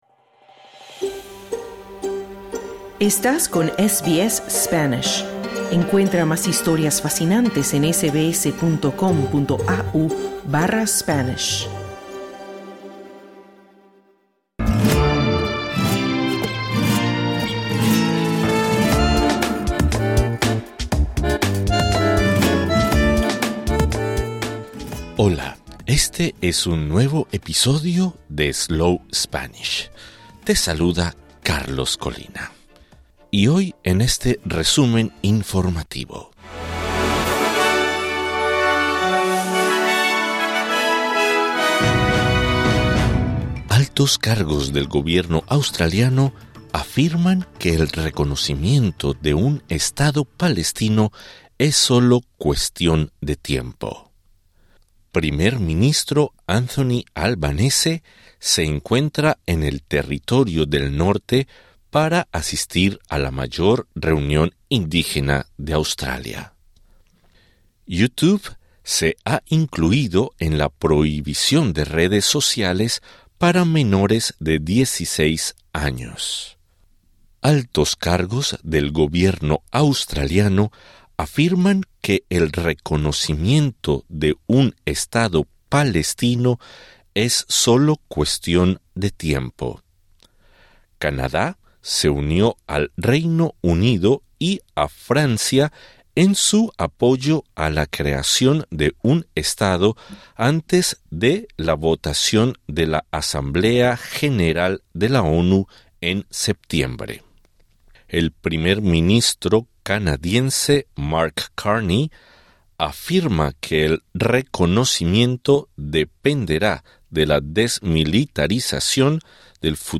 Slow Spanish | Weekly news flash | 1 August 2025
Spanish News Bulletin - Boletín de noticias en español En este boletín: Altos cargos del gobierno australiano afirman que el reconocimiento de un Estado palestino es sólo cuestión de tiempo.